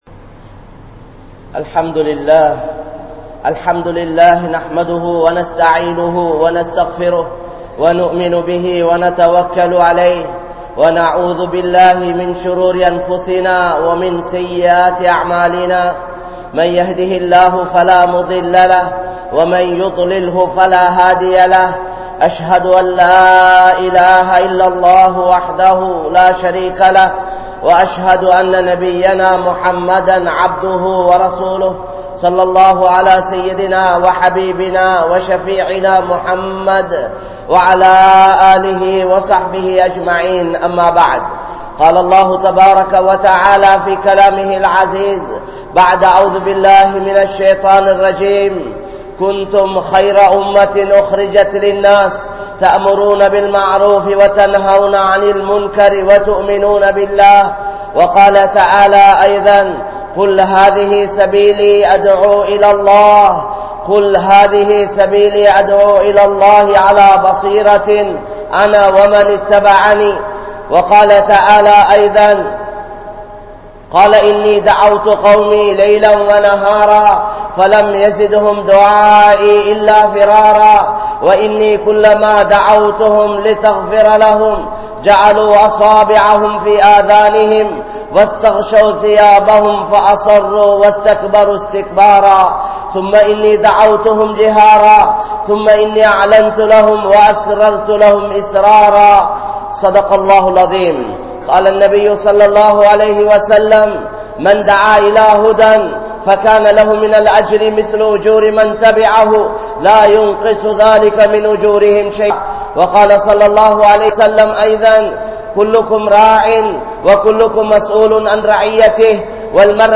Qualities of a Dhaee (ஒரு இஸ்லாமிய அழைப்பாளனின் குணங்கள்) | Audio Bayans | All Ceylon Muslim Youth Community | Addalaichenai
Grand Jumua Masjith